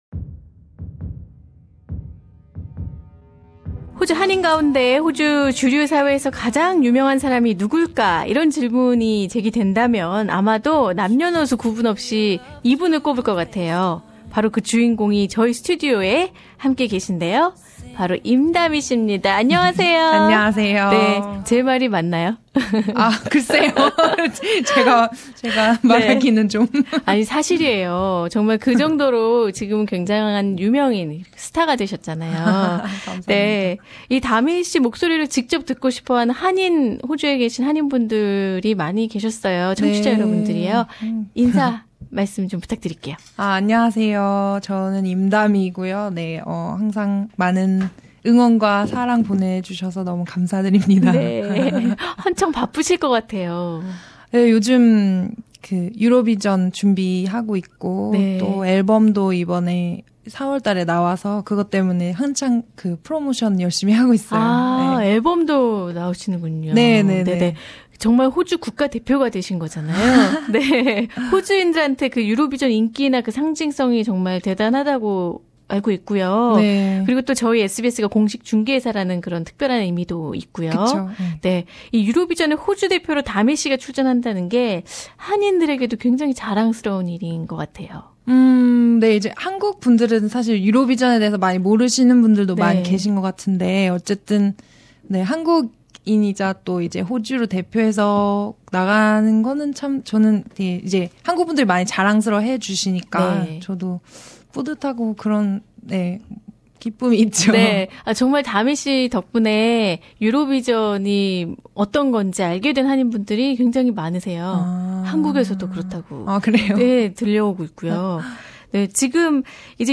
X-factor winner Dami Im has expressed her sincere appreciation of Korean Australian community's support for her achievements in an exclusive interview with SBS Radio Korean Program.